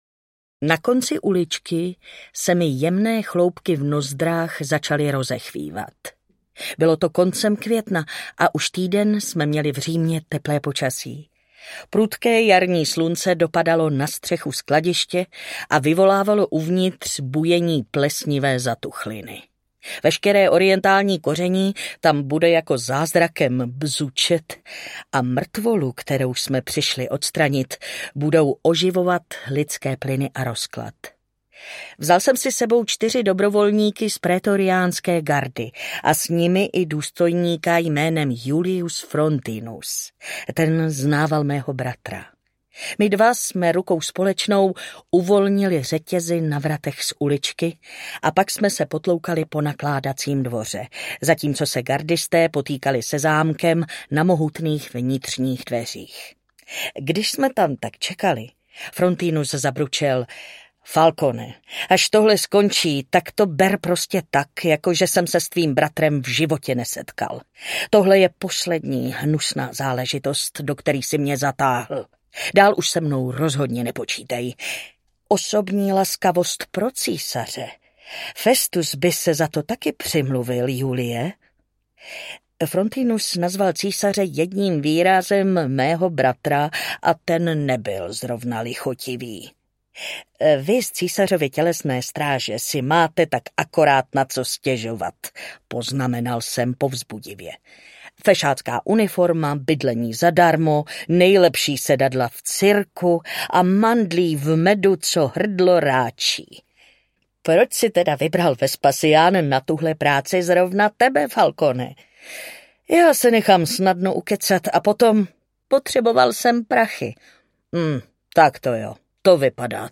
Bronzové stíny audiokniha
Ukázka z knihy
Čte Martina Hudečková.
Vyrobilo studio Soundguru.